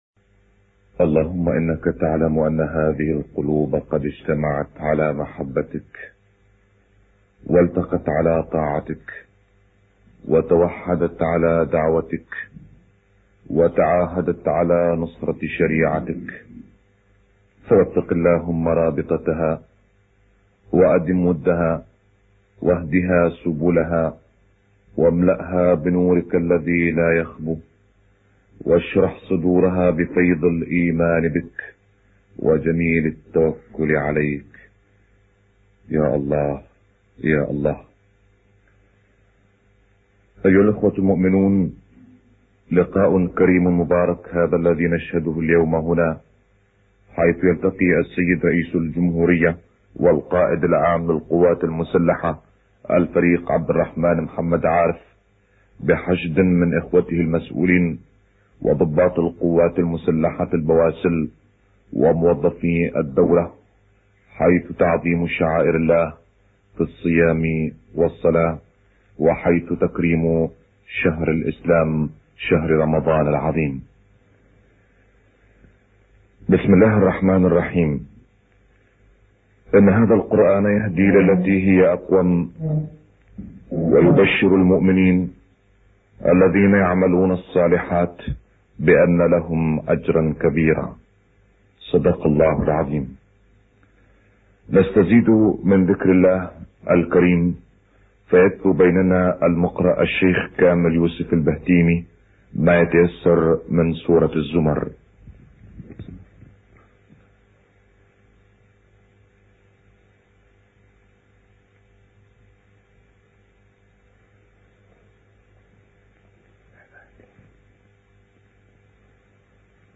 گروه فعالیت‌های قرآنی: تلاوتی از شیخ کامل یوسف البهتیمی در قصر ریاست جمهوری بغداد در کشور عراق ارائه می‌شود.